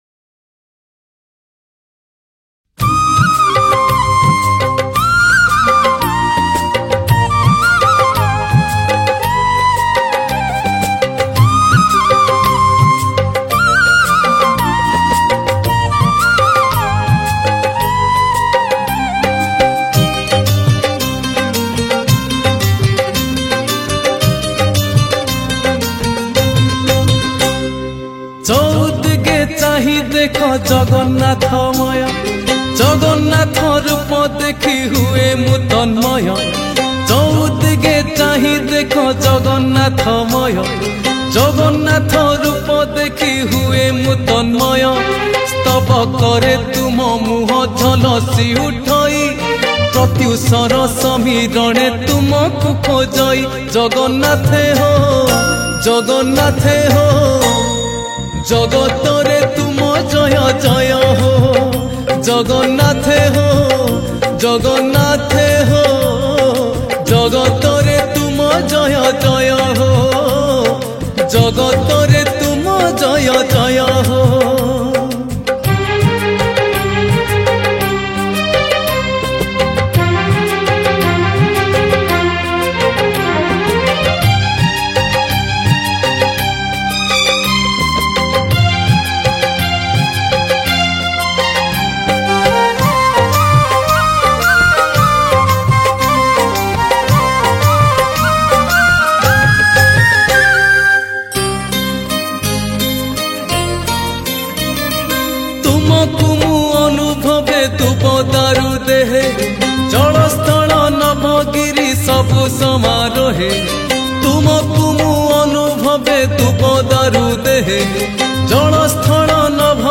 Category : Odia Bhajan Song